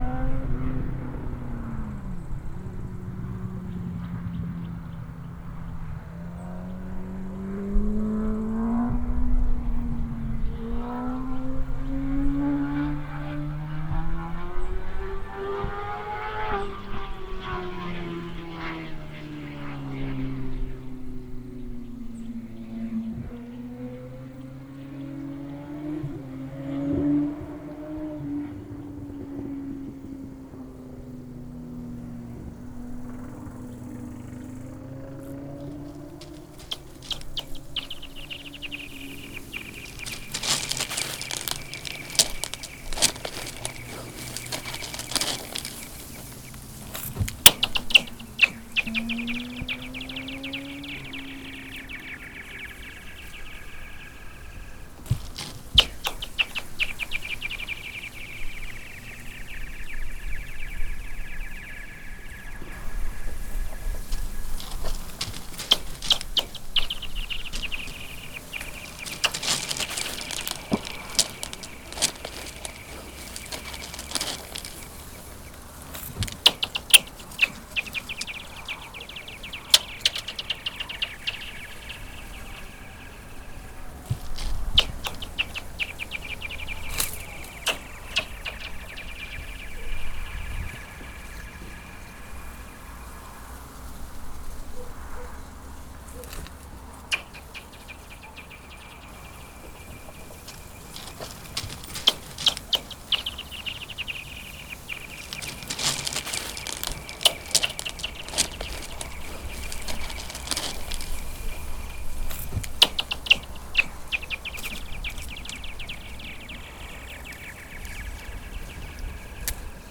Pokud je led tenký právě tak akorát, funguje jako blána na bubnu..
led voda motor periferie Autor